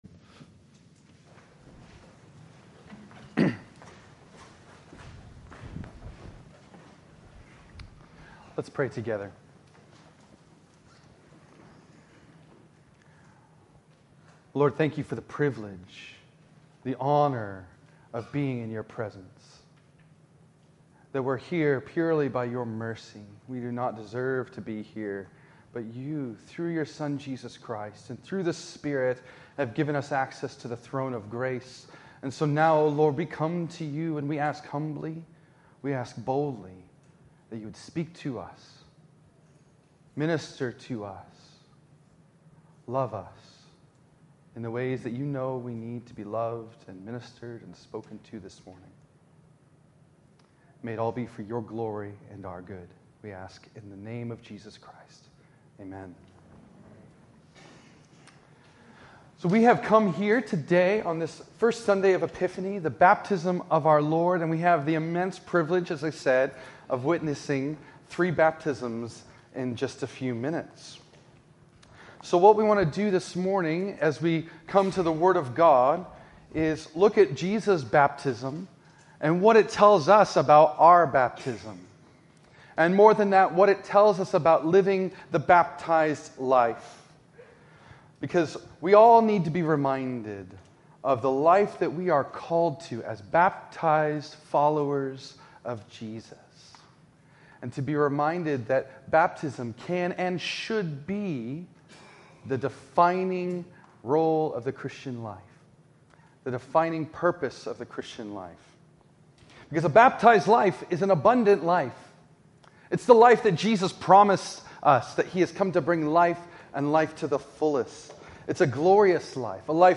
In this sermon on the first Sunday of Epiphany (the Baptism of our Lord)